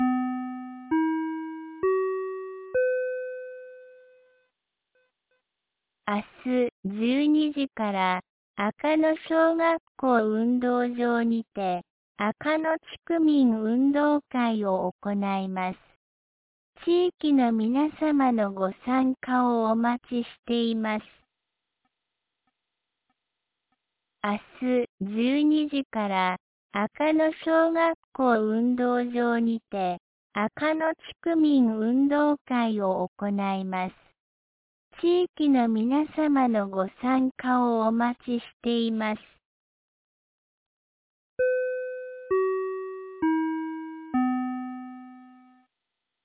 2025年10月25日 12時15分に、安芸市より赤野へ放送がありました。